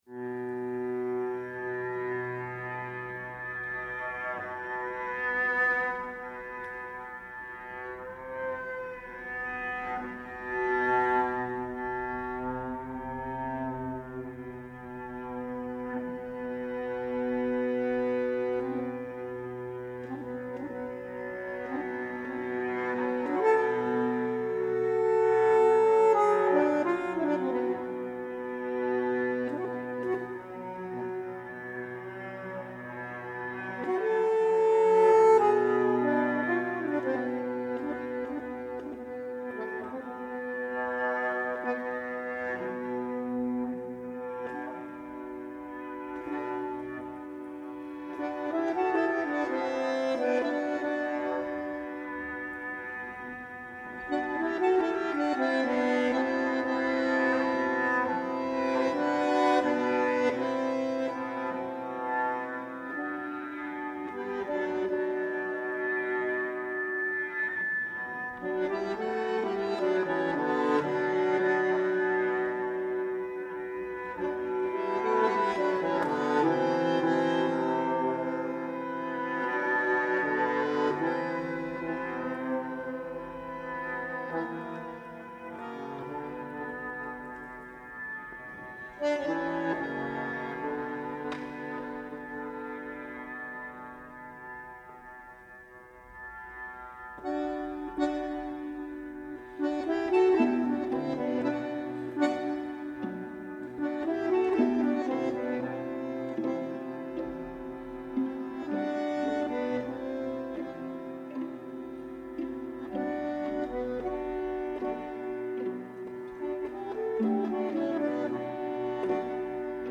bandoneon
violino
viola
violoncello